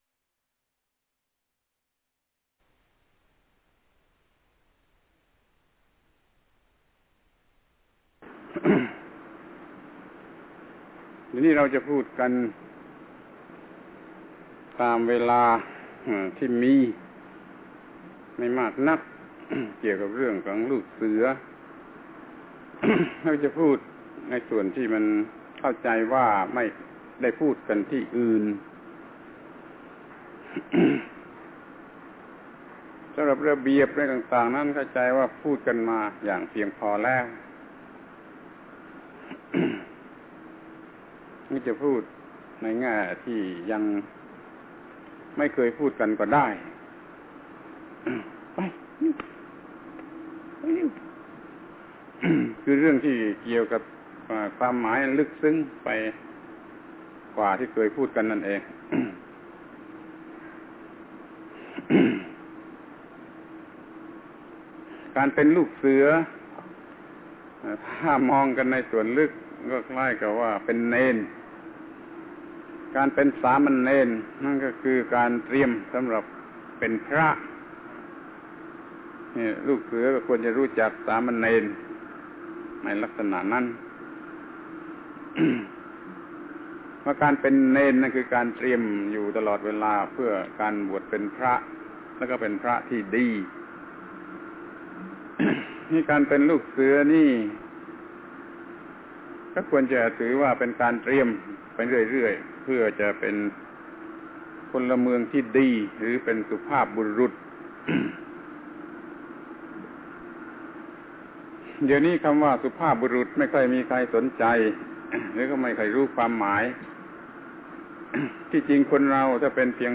พระธรรมโกศาจารย์ (พุทธทาสภิกขุ) - อบรมลูกเสือ ค่ายธรรมบุตร เรื่อง ลูกเสือคือการเตรียมพร้อมไปสู่การเป็นสุภาพบุรุษ